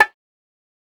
OZ-Perc (Come).wav